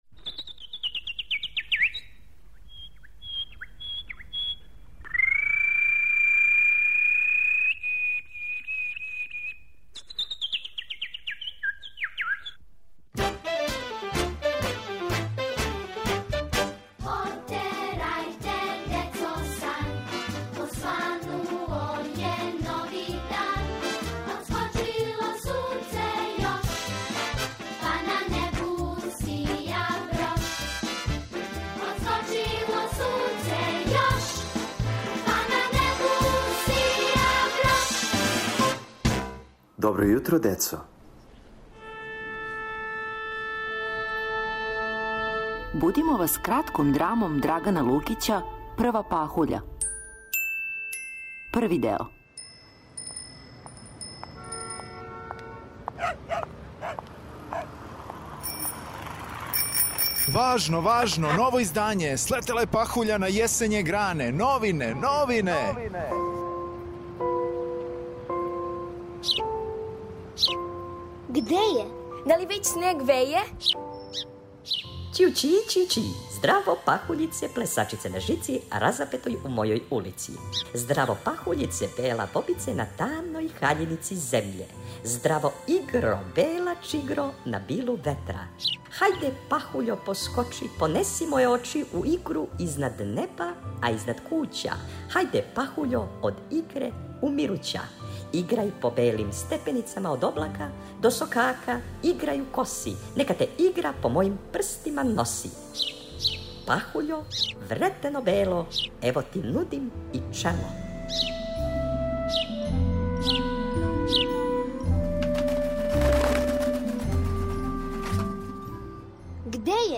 Сазнајте да ли ће деца успети да ухвате пахуљу пре него што се истопи - у драми за децу Драгана Лукића "Прва пахуља".